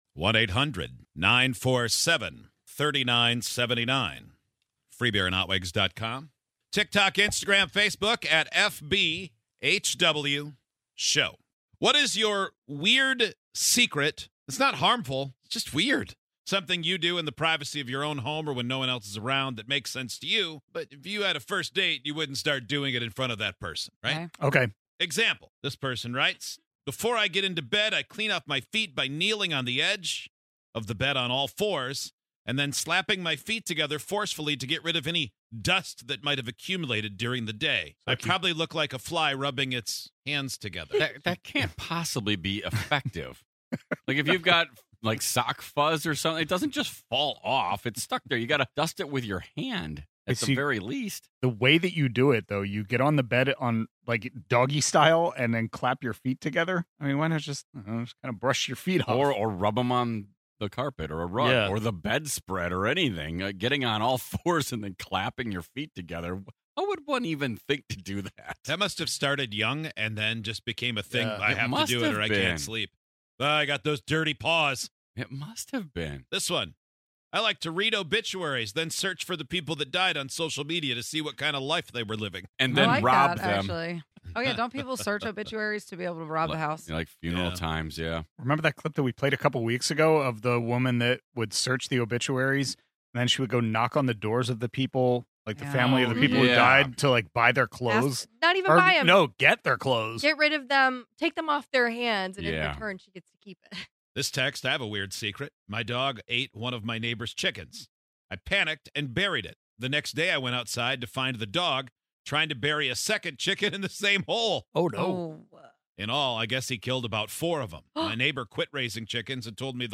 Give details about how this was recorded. Everyone’s got weird habits, but some are so unhinged they stay locked in the “do not tell anyone ever” vault...unless, of course, they’re on a national radio show. This morning, we asked people to get weird with us and they did not disappoint!